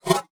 Select Scifi Tab 12.wav